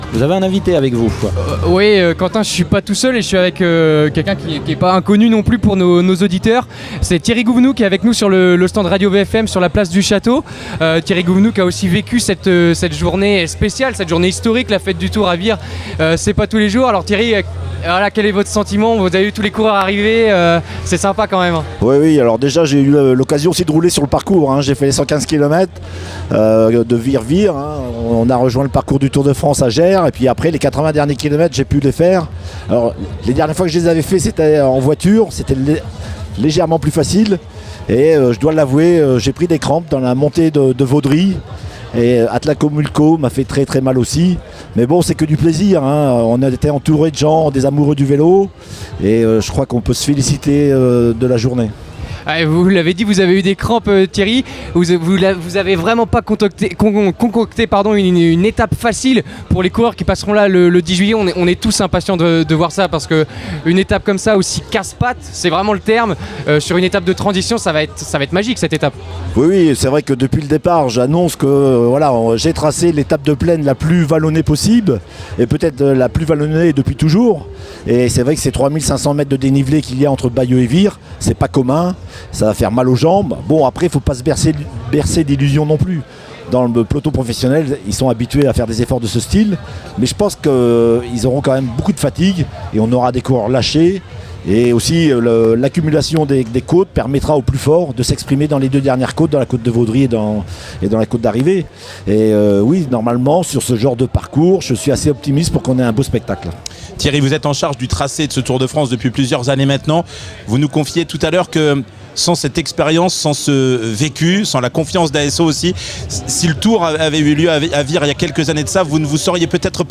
en direct de la Fête du Tour